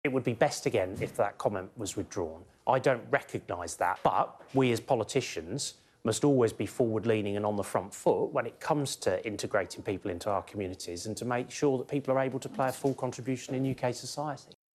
Office minister Tom Pursglove talking about MP for Sutton and Cheam, Paul Scully.